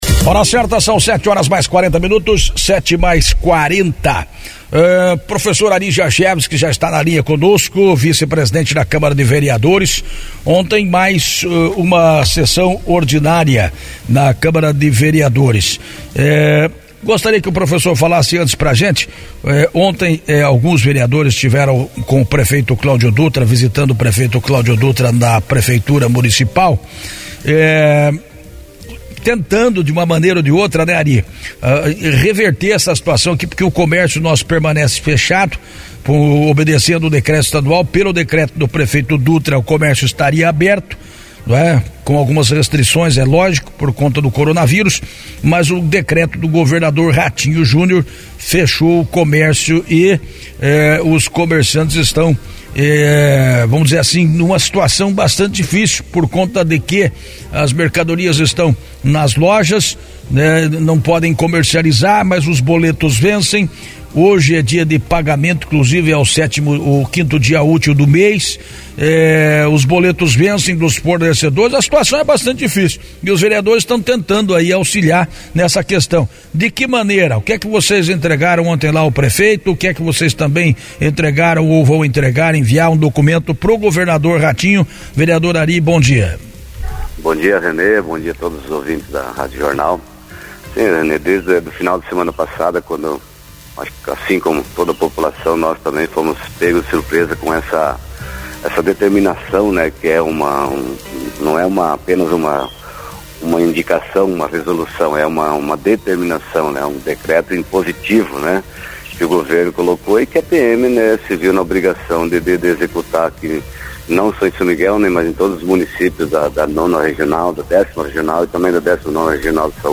Hoje (07) no Jornal da Manhã, o vice-presidente da “casa de leis”, professor Ari Jarczewski, fez um balanço da sessão, bem como avaliou a atual situação do comércio de nossa cidade, enfrentando o decreto estadual que impõe fechamento das lojas que vendem produtos considerados não essenciais.